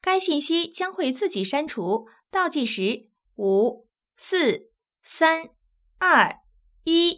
ivr-message_self_destruct.wav